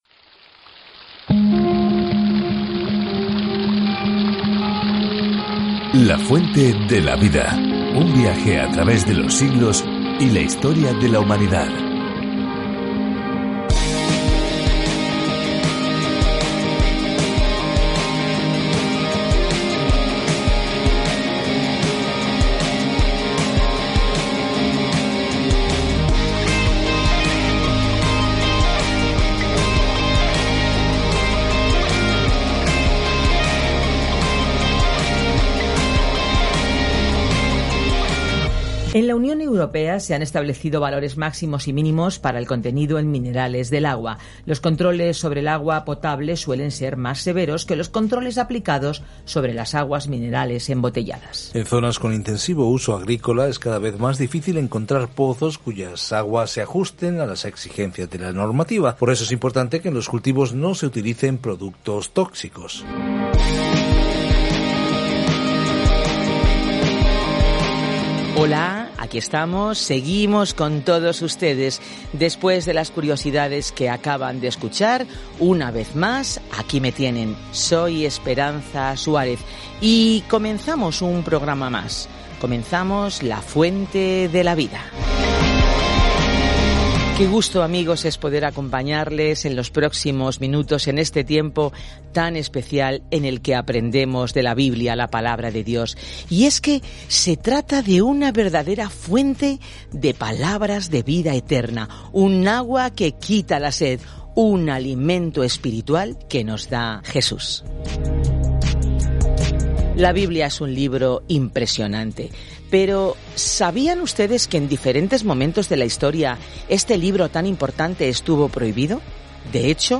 Escritura ÉXODO 9:8-35 ÉXODO 10:1-9 Día 9 Iniciar plan Día 11 Acerca de este Plan Éxodo narra el escape de Israel de la esclavitud en Egipto y describe todo lo que sucedió en el camino. Viaja diariamente a través de Éxodo mientras escuchas el estudio de audio y lees versículos seleccionados de la palabra de Dios.